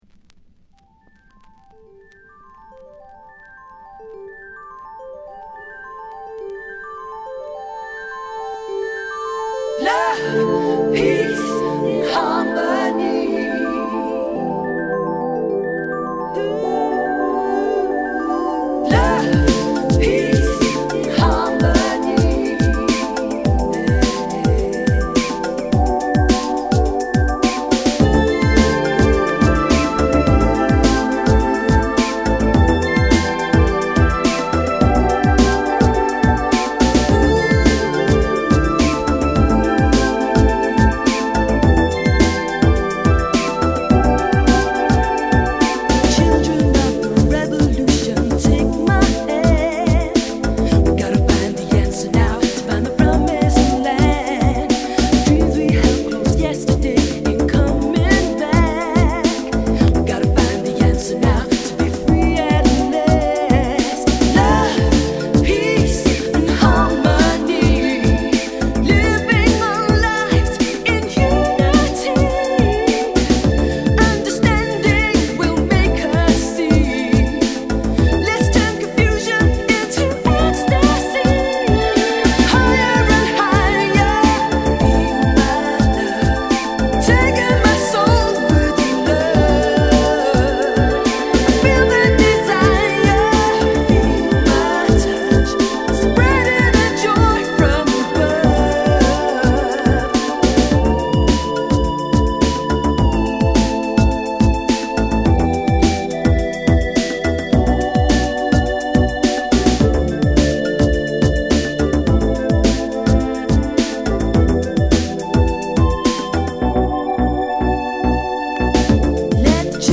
グラウンドビートチルアウト